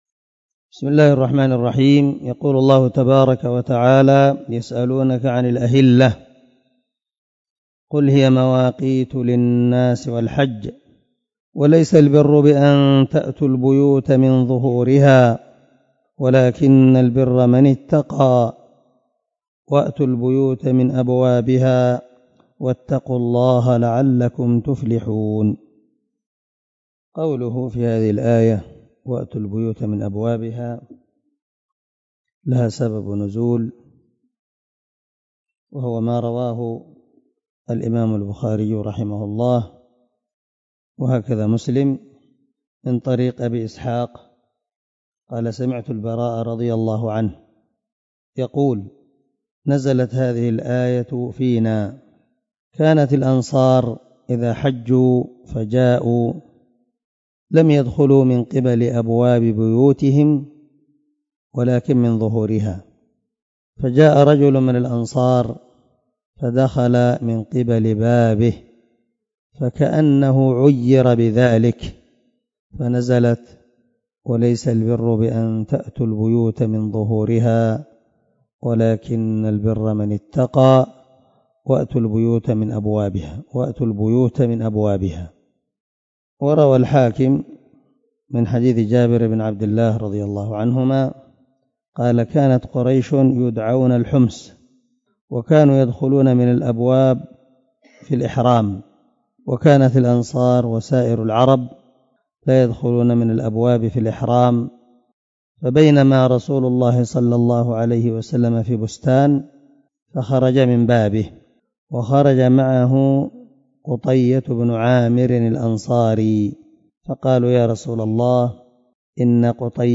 087الدرس 77 تفسير آية ( 189 ) من سورة البقرة من تفسير القران الكريم مع قراءة لتفسير السعدي